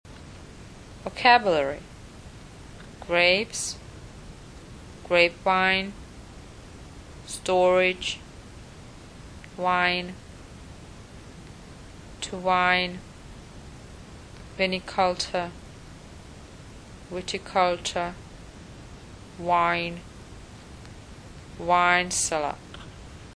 Nahrávka výslovnosti (*.MP3 soubor):